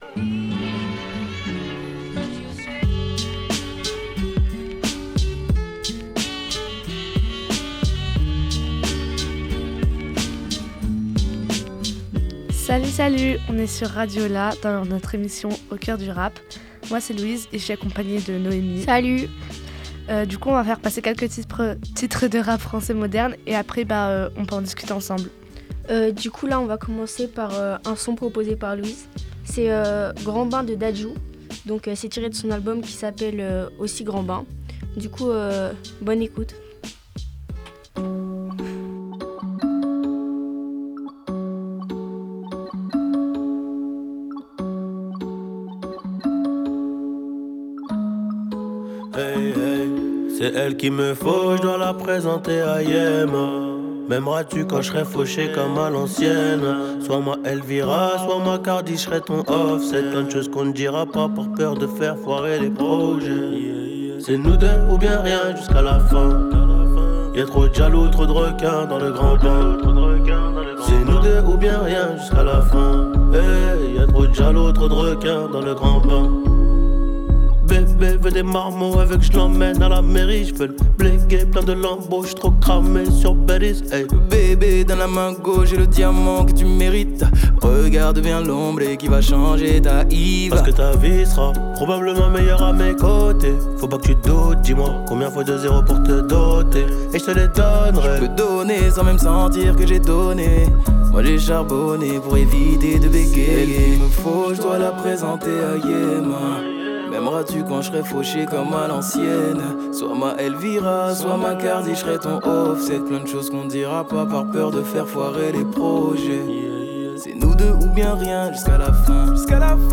Une première émission qui vous propose une sélection bien personnelle de rap actuel